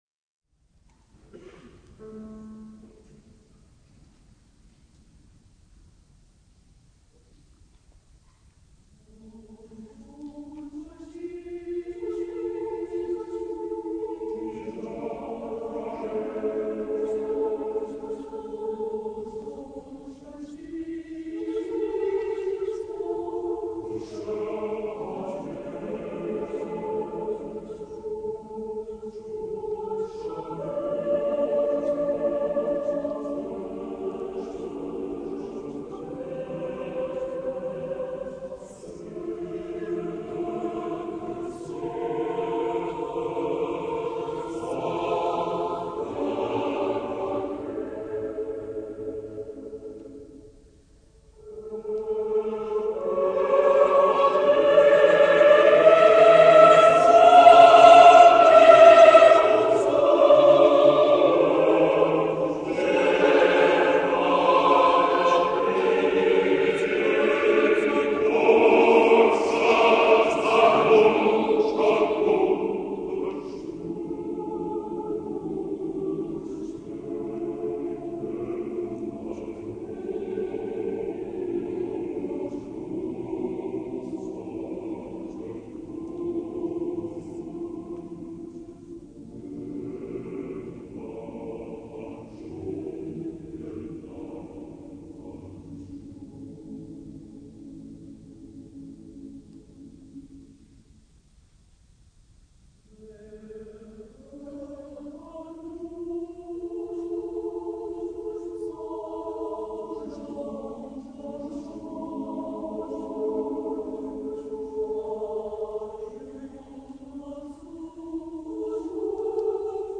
a cappella choral work
Eagle Rock, California. A song to comfort the sad heart.